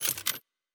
pgs/Assets/Audio/Sci-Fi Sounds/Weapons/Weapon 06 Reload 2.wav at 7452e70b8c5ad2f7daae623e1a952eb18c9caab4
Weapon 06 Reload 2.wav